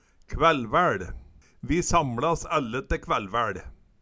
Høyr på uttala Ordklasse: Substantiv hankjønn Kategori: Hushald, mat, drikke Attende til søk